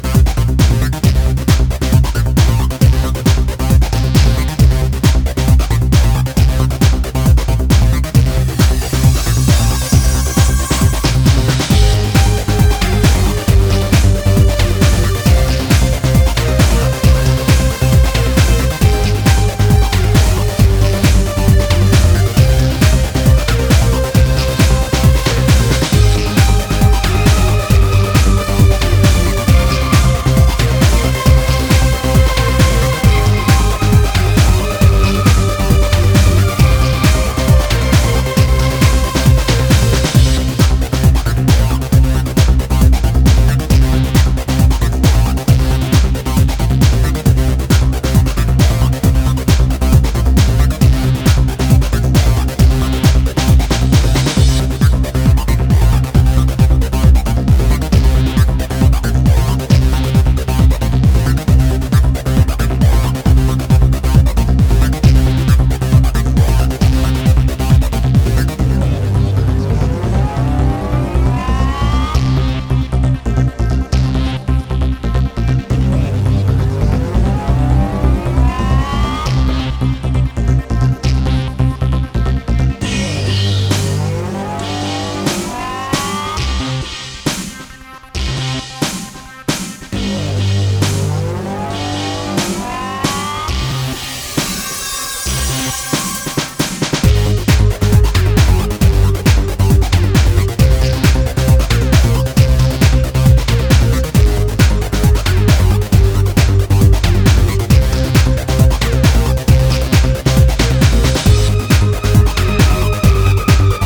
Neo Goa / Progressive Trance tracks
aery Drum’n’bass experimentations